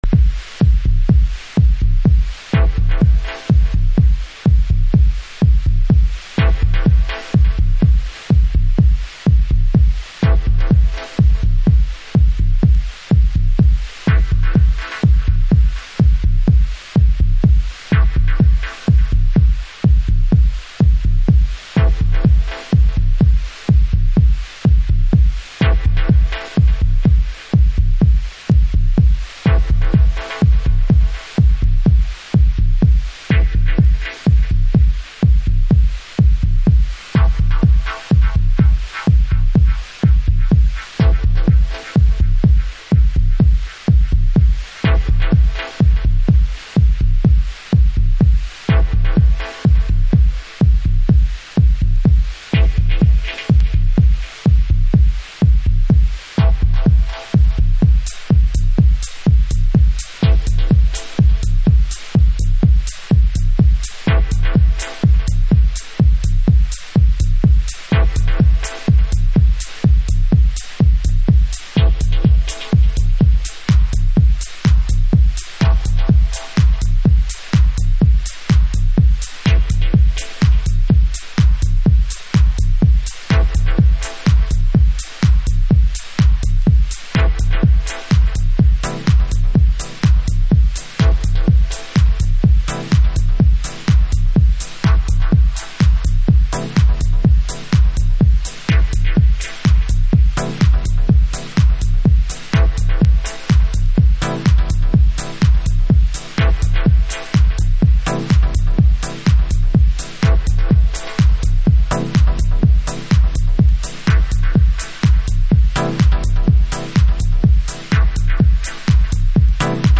House / Techno
攻めのシンセウェーヴが心地良い。
経てきたダブテクノです。